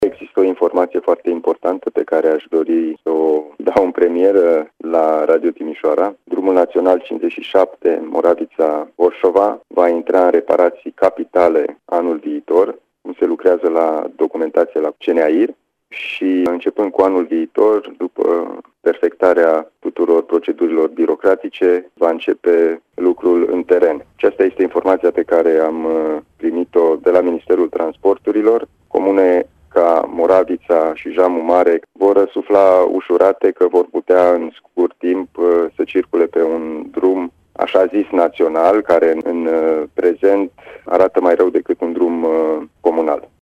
Drumul Național 57 care face legătura între Moravița și Orșova va intra în reparații capitale anul viitor. Anunțul a fost făcut, la Radio Timișoara, de Alin Nica,președintele Consiliului Județean Timiș, care a subliniat că drumul arată mai rău decât un drum communal.